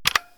key-press-2.wav